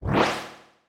motor_upper_2.wav